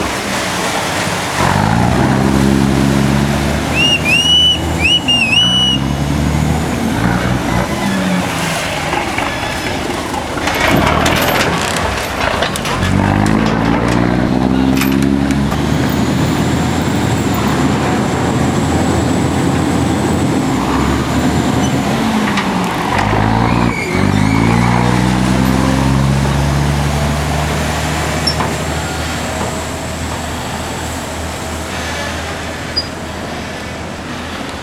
transport
Harbour Ferryboat Leave